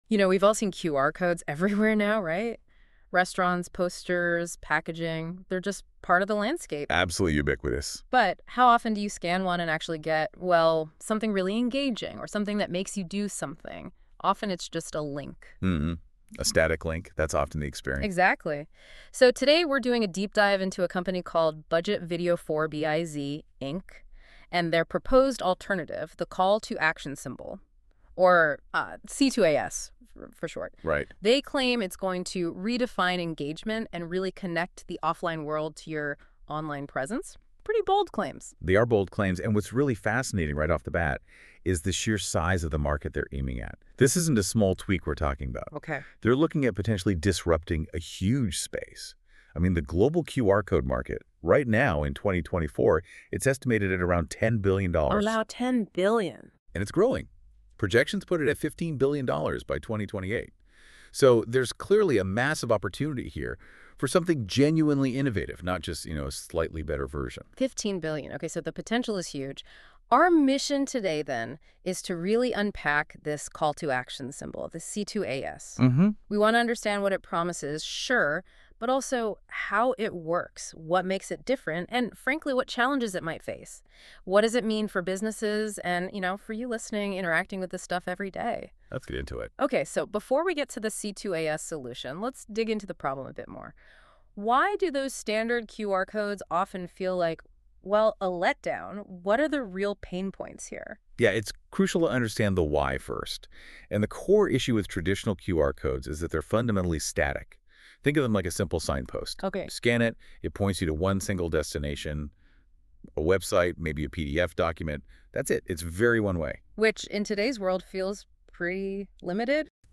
WHY STANDARD — Free — QR Codes — ARE LETTING YOU DOWN! Radio Broadcast